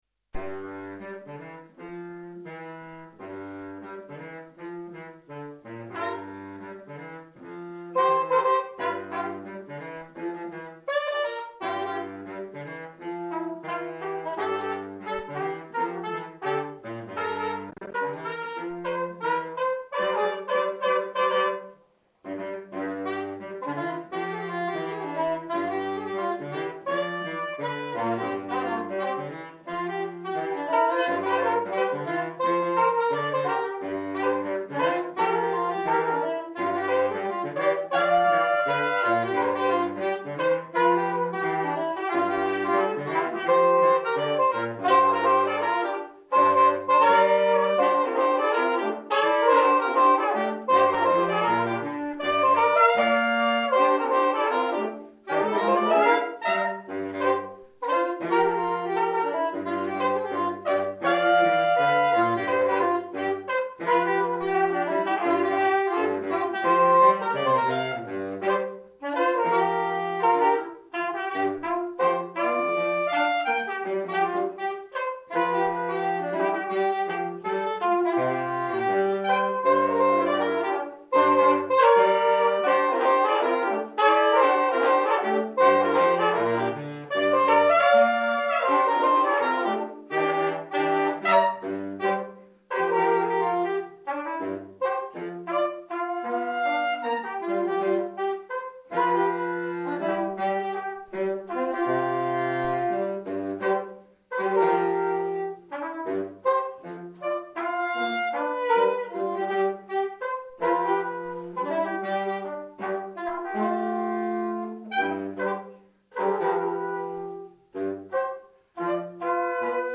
ss, bars, tp/flh, !perf